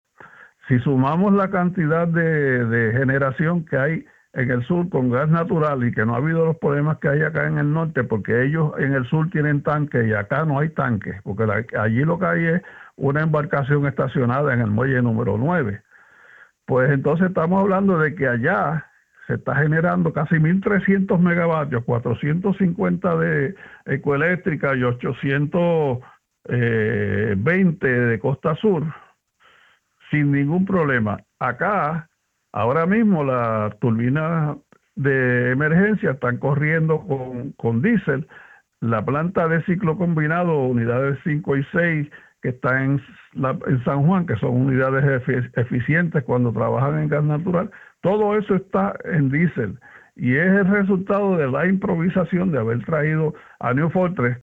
en entrevista con Radio Isla.